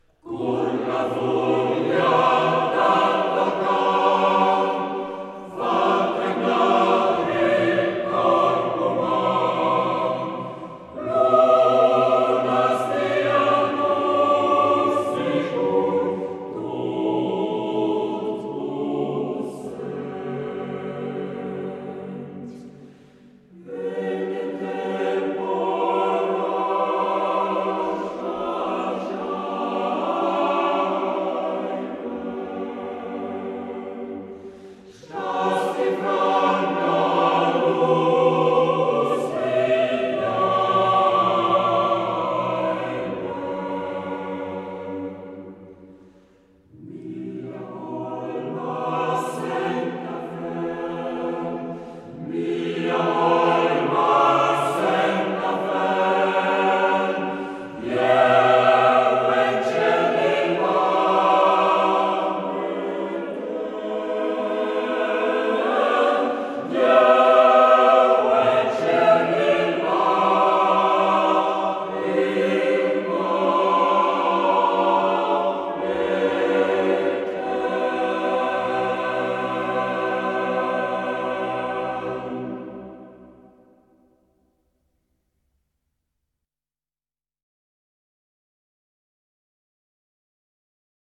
Chor da concert grischun.